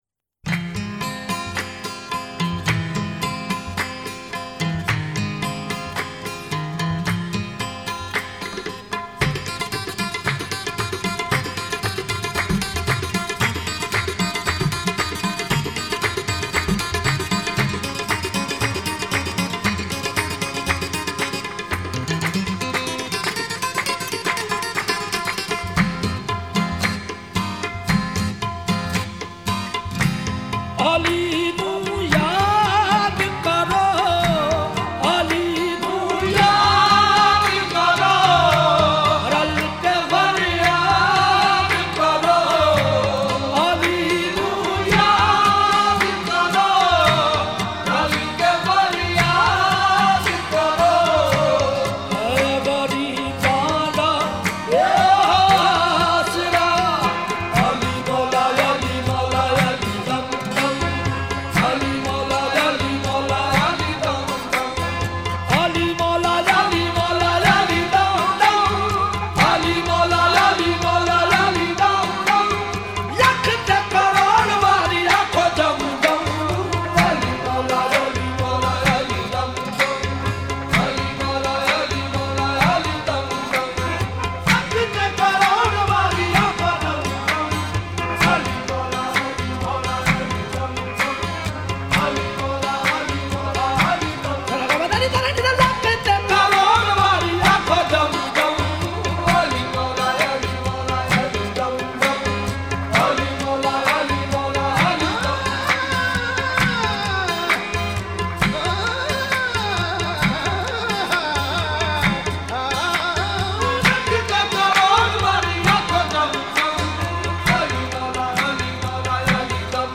qawwali
It is one the most simple tunes